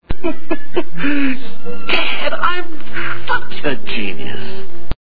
The sound bytes heard on this page have quirks and are low quality.